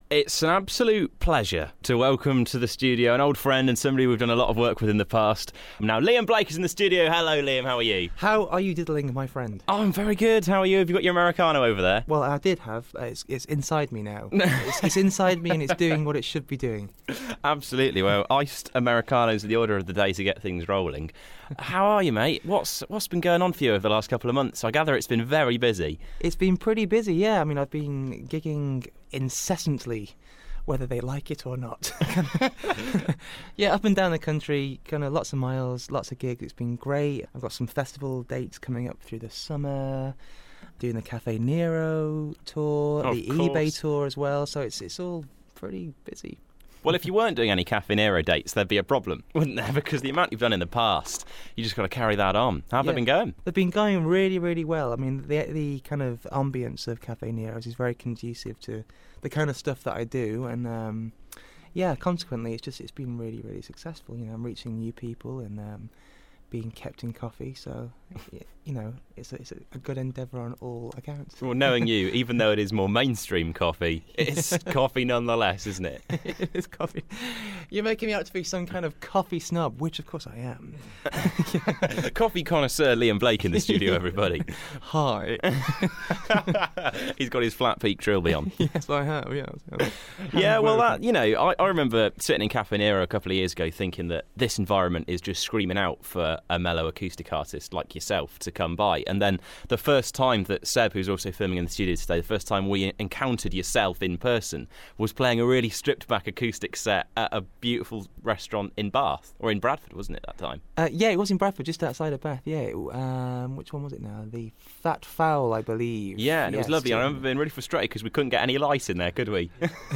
Interview & Session
dropped by the studio to talk about a grittier new sound to his music and perform an acoustic track.